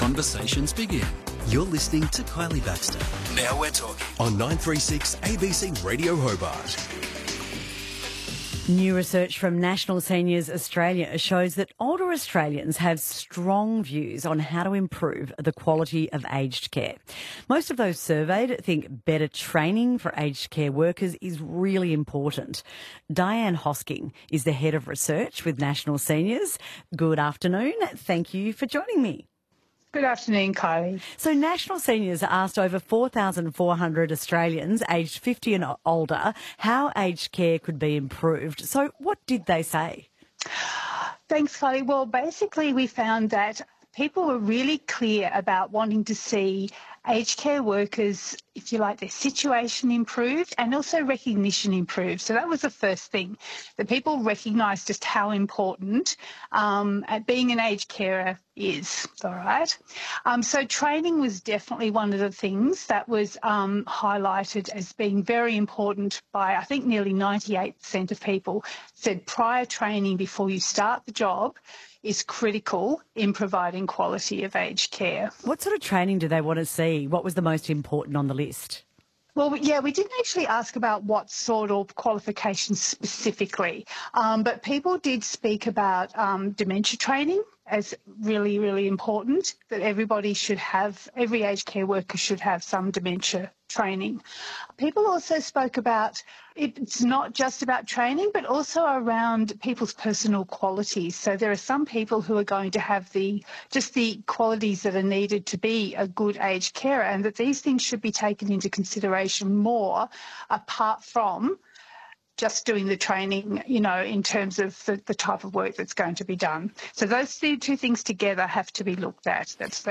interview with ABC Radio Hobart Drive here.